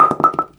bowlingPinFall_r_1.wav